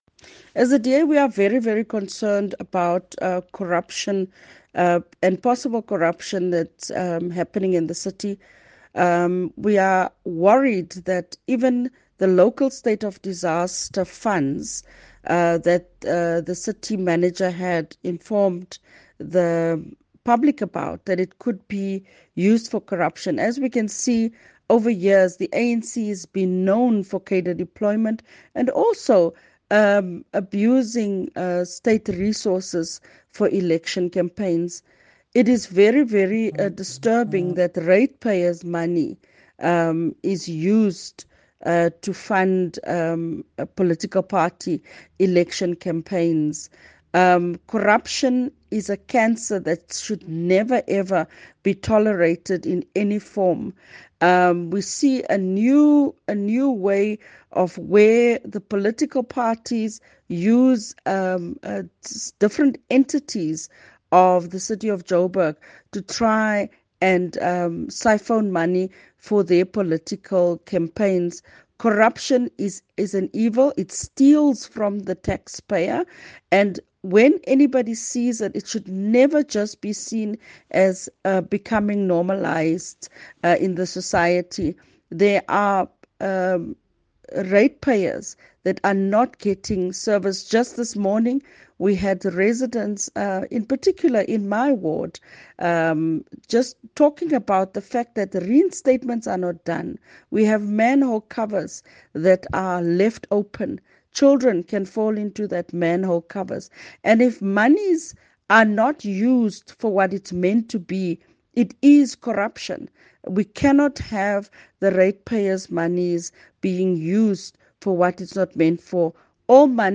Find a soundbite by DA Jhb Caucus Leader, Belinda Kayser-Echeozonjoku in English